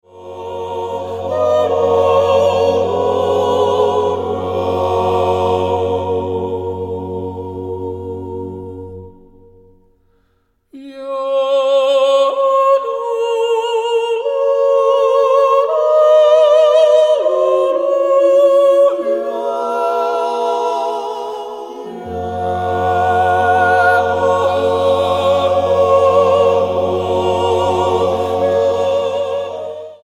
Genre: Chormusik / Jodel
Genre / Stil: Chor